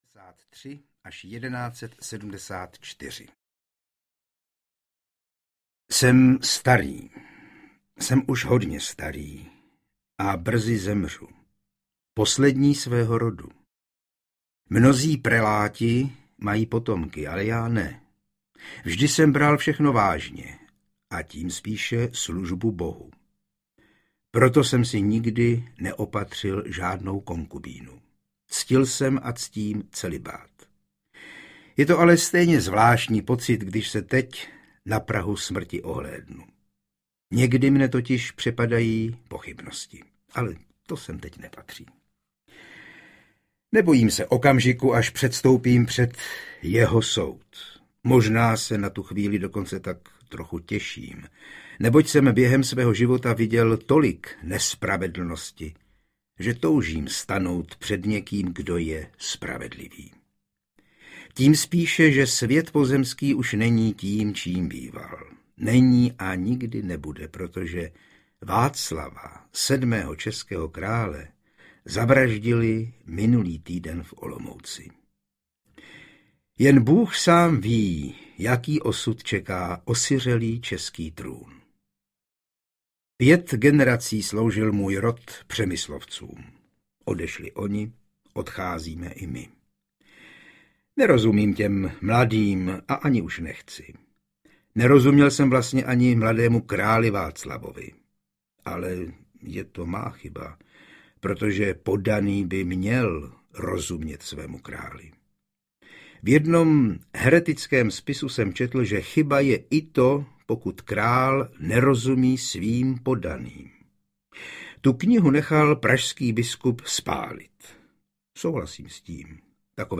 Přemyslovská epopej – komplet audiokniha
Ukázka z knihy
premyslovska-epopej-komplet-audiokniha